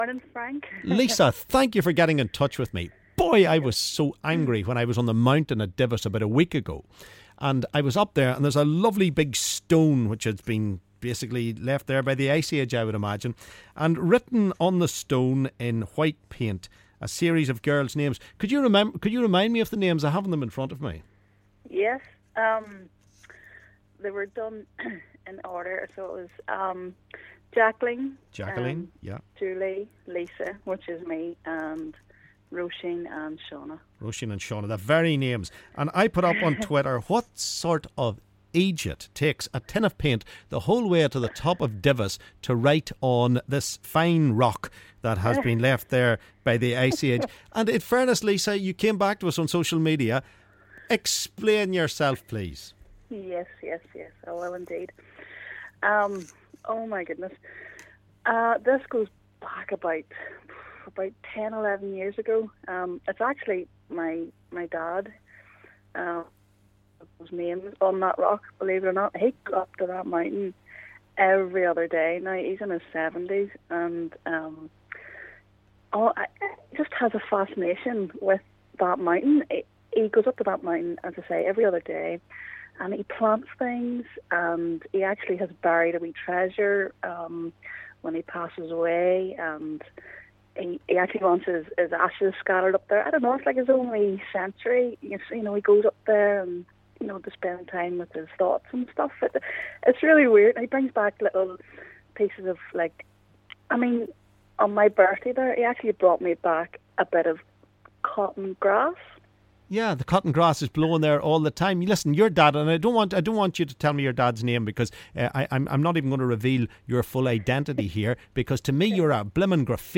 LISTEN ¦ Caller explains the meaning behind Divis Mountain graffiti